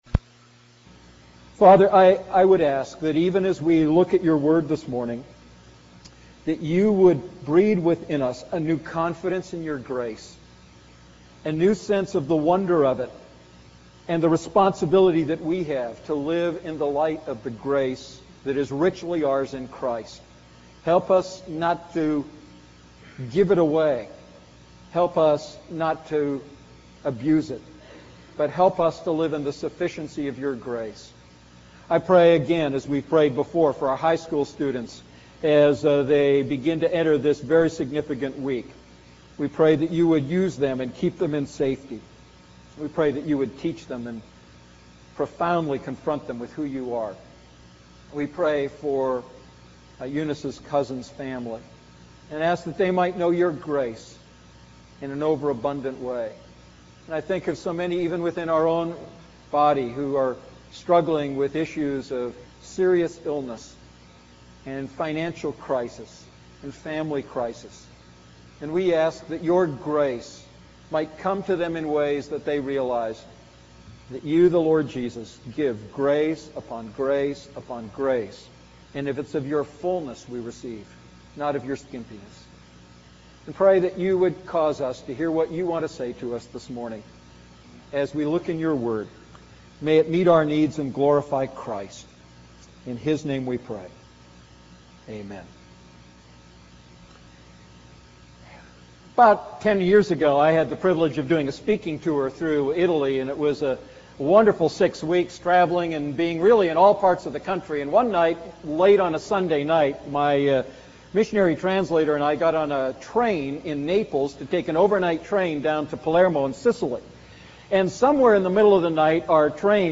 Message: “True Freedom